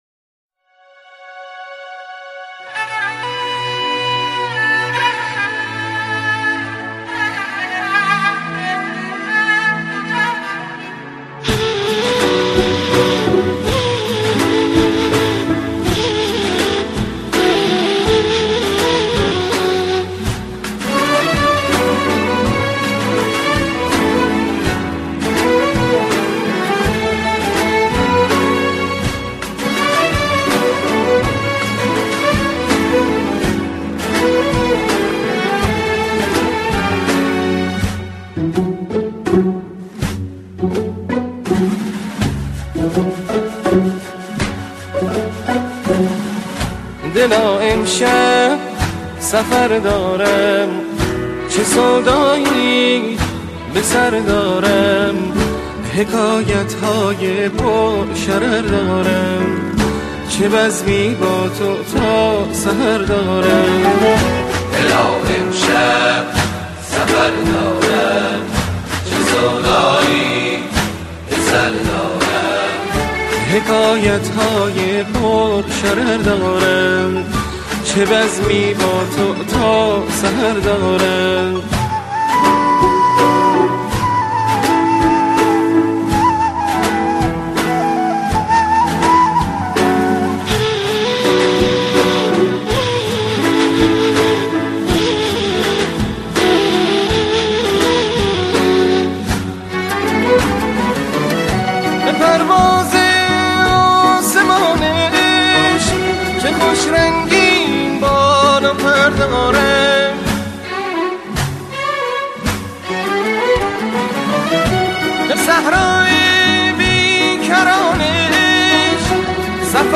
** موسيقي پاپ و فولكلور **